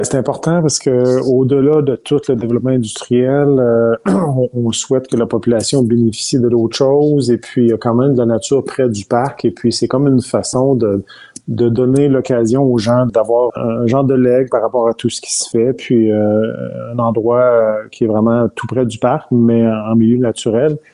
C’est ce qui a été annoncé en conférence de presse.